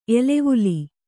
♪ elevuli